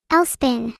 Add neuro voicepack